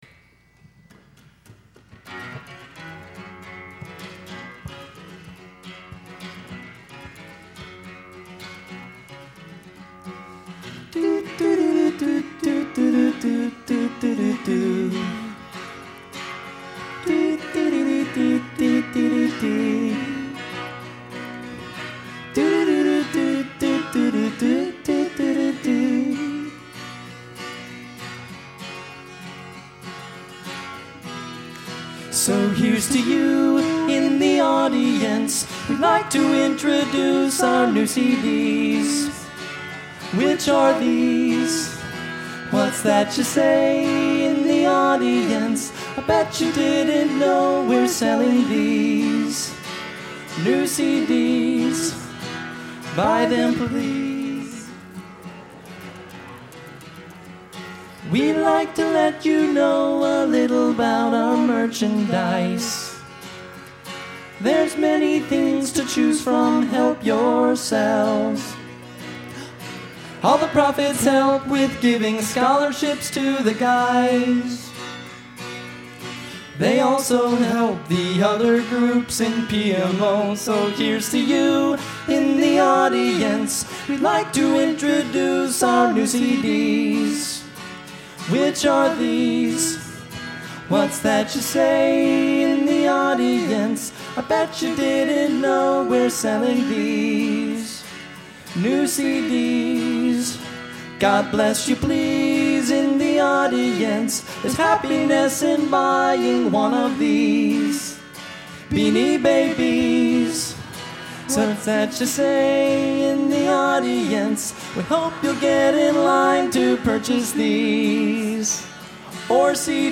Location: Northrop High School, Fort Wayne, Indiana